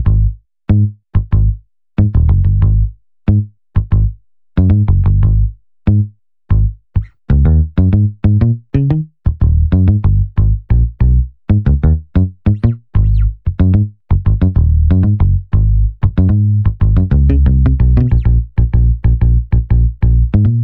09 bass A2.wav